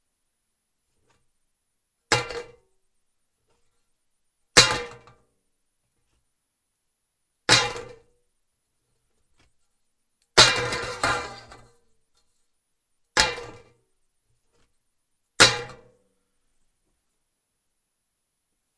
铿锵有力
描述：将锡盖放在锡上
Tag: 金属 金属盖 下落